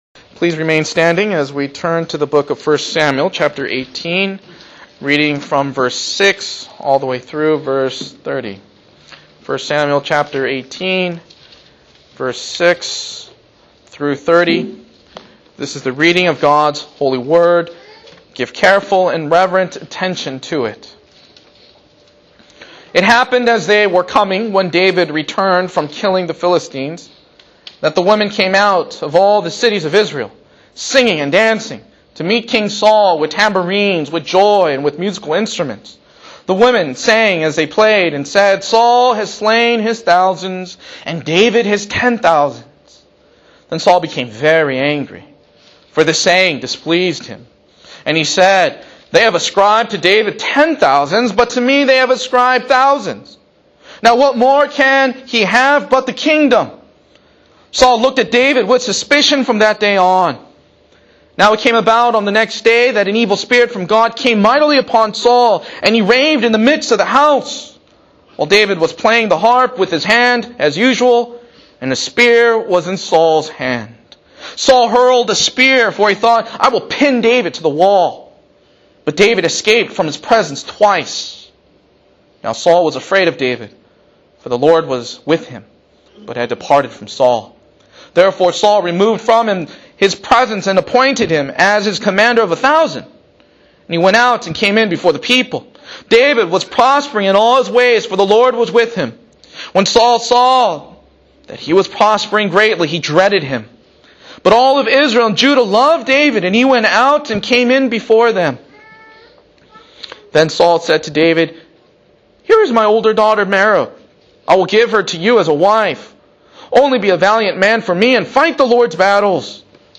Download MP3 (Right click on the link and select "Save Link As") Labels: Sermon - Guest Speakers